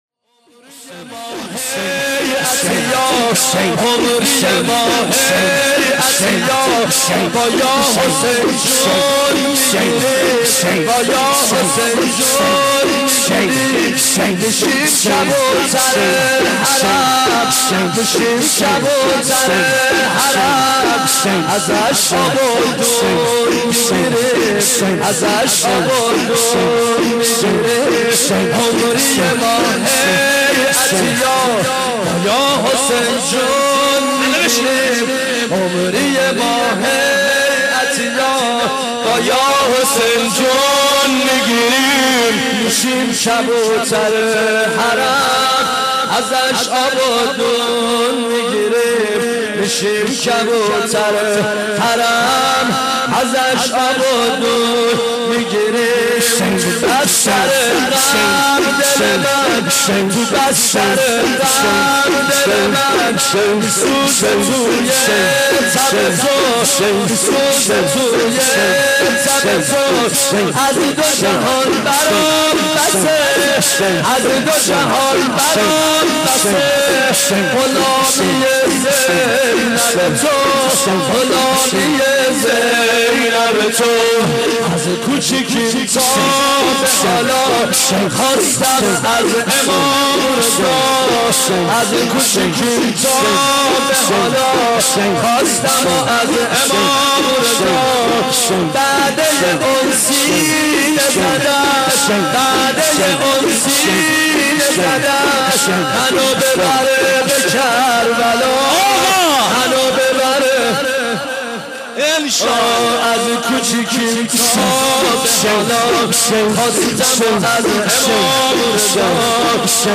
• محرم 86
دانلود مداحی